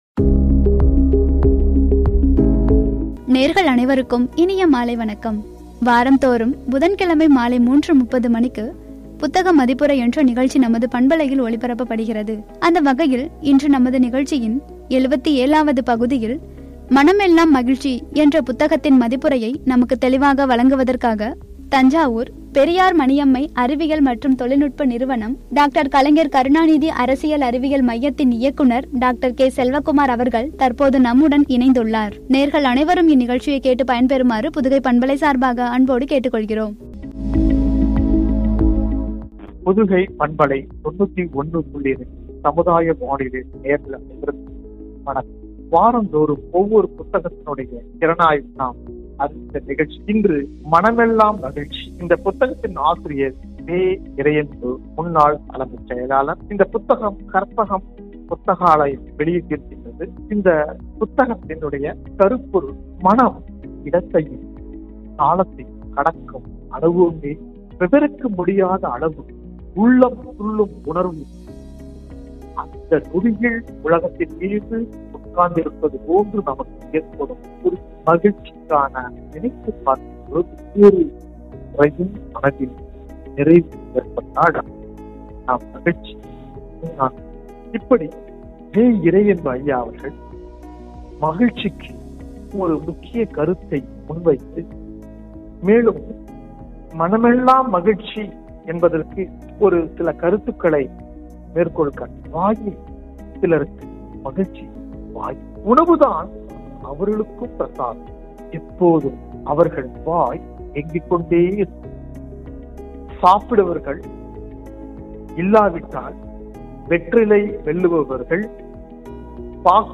“மனமெல்லாம் மகிழ்ச்சி” புத்தக மதிப்புரை (பகுதி –77), என்ற தலைப்பில் வழங்கிய உரை.